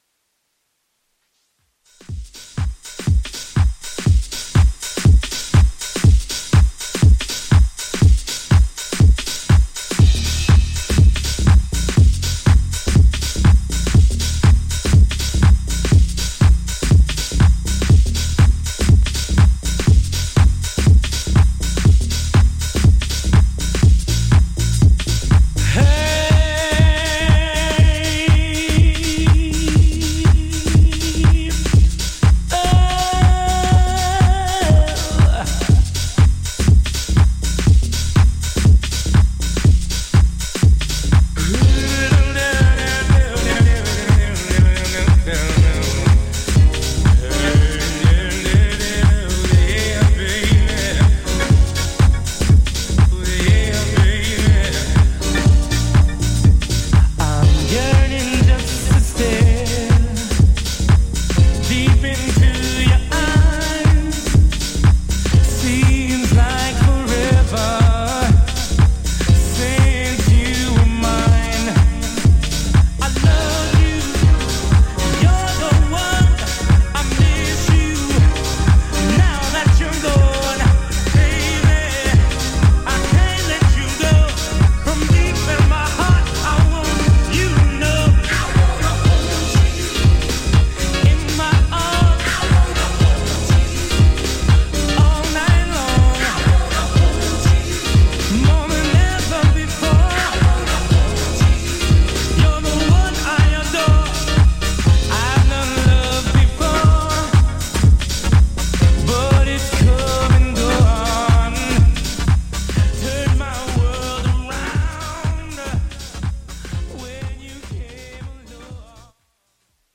ジャンル(スタイル) CLASSIC HOUSE